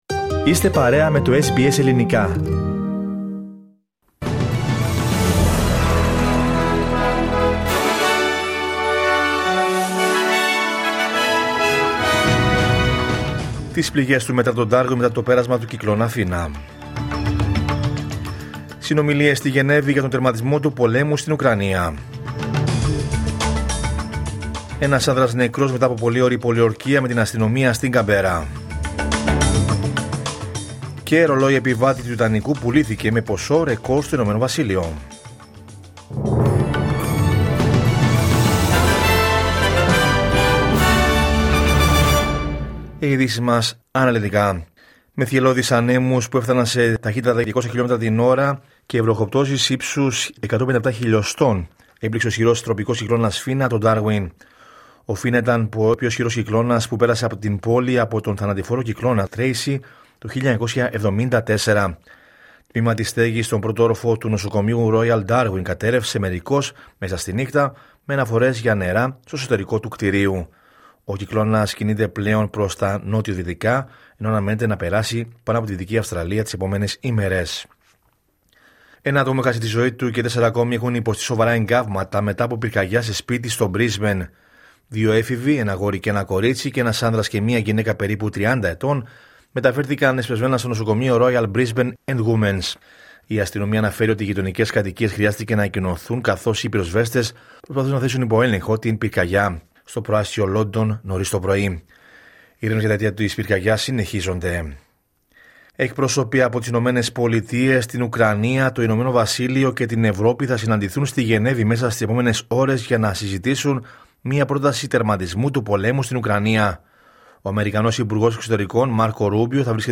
Ειδήσεις από την Αυστραλία, την Ελλάδα, την Κύπρο και τον κόσμο στο Δελτίο Ειδήσεων της Κυριακής 23 Νοεμβρίου 2025.